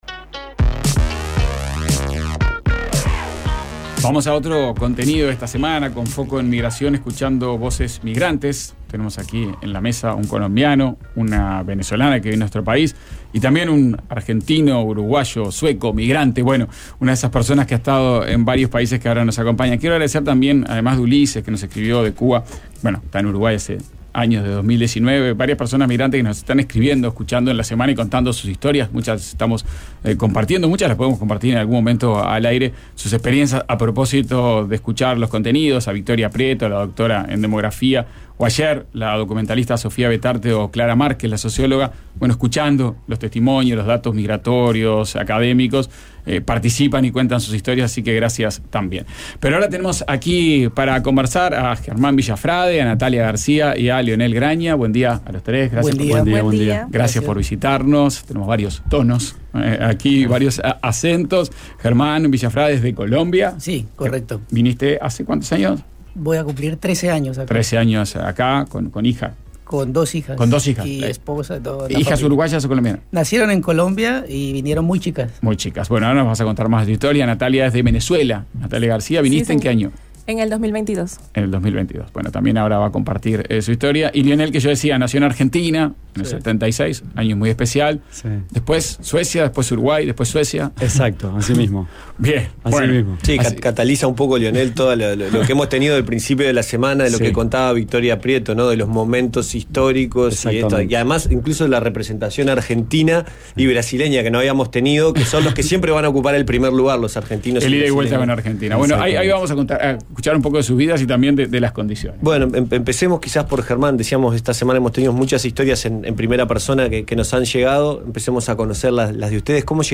Conversamos con un colombiano, una venezolana y un uruguayo que nació en el exilio y emigró en la crisis económica. Tres historias diferentes y ahora un presente común en Montevideo.